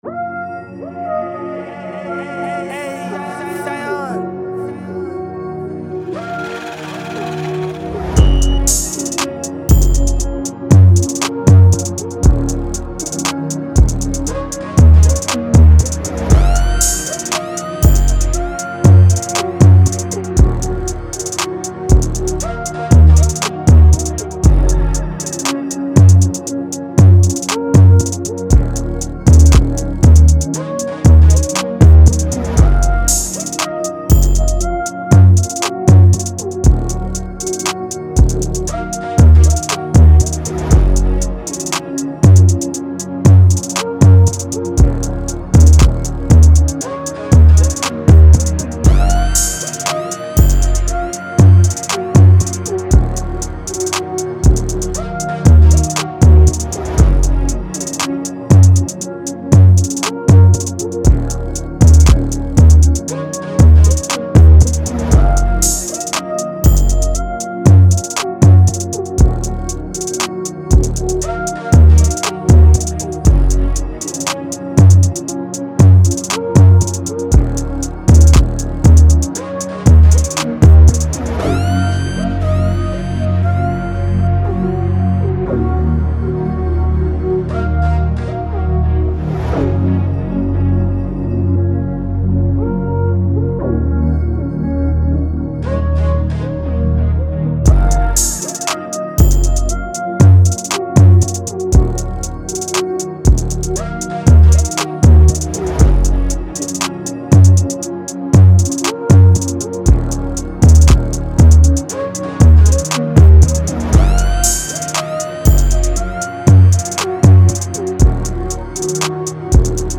Plug
118 D# Minor